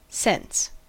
Ääntäminen
US : IPA : [sɛns]